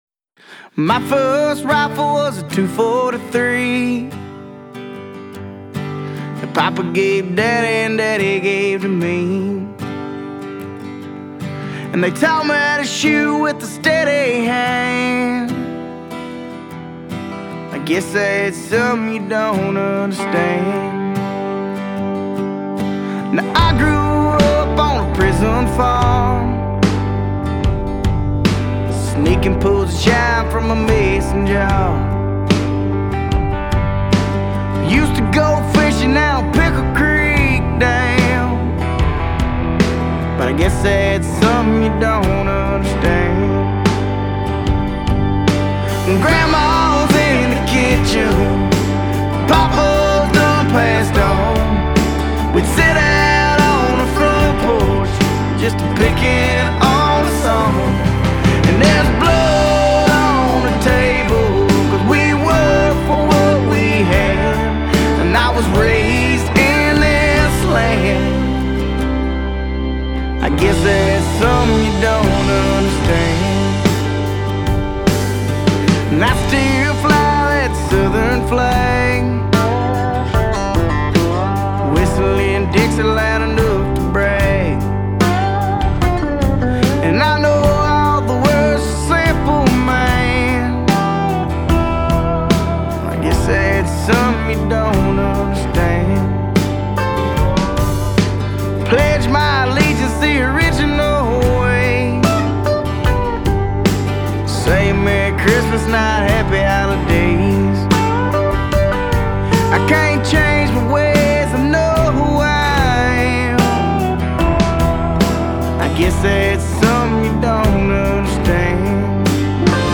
Southern Country Rock